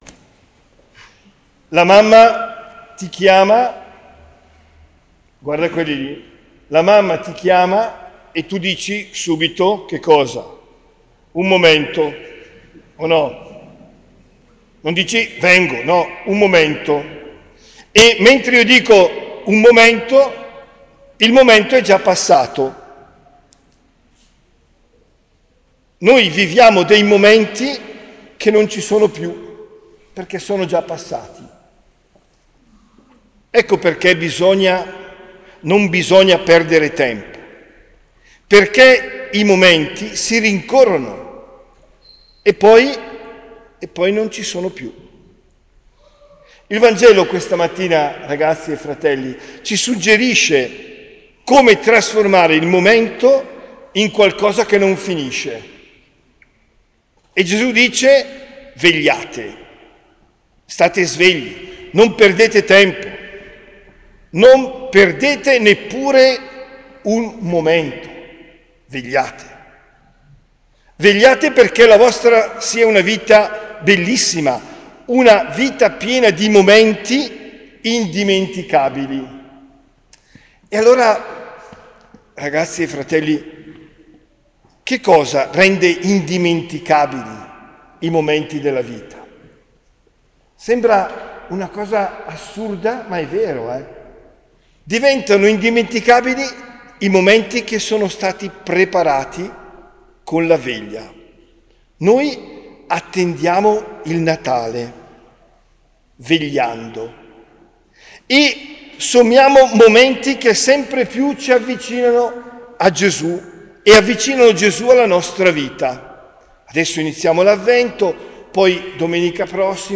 OMELIA DEL 03 DICEMBRE 2023